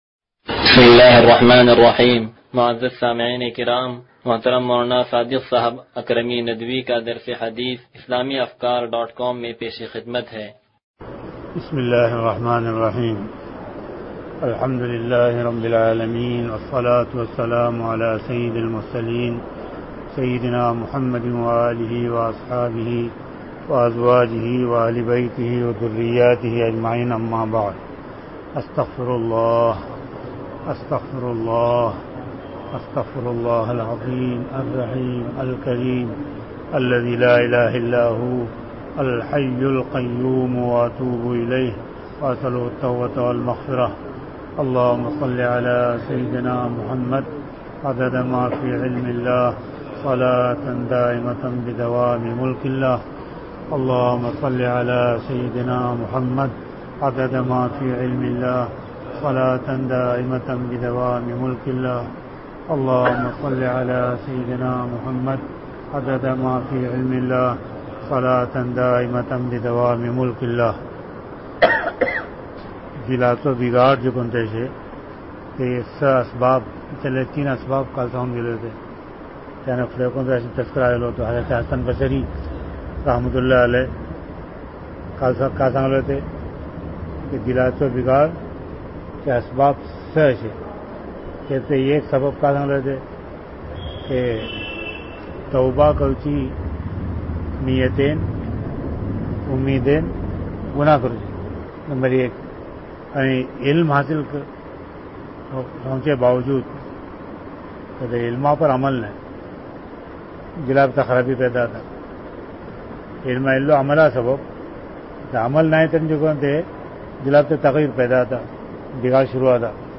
درس حدیث نمبر 0169